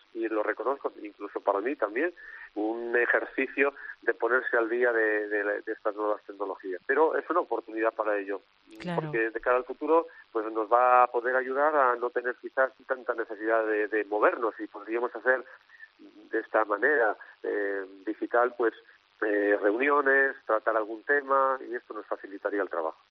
En una entrevista en Cope Santiago,subrayaba que, como muchas personas, hay curas que están pasando la crisis totalmente solos, así que este encuentro sacerdotal en la red les permite reforzar vínculos y sobrellevar mejor el aislamiento.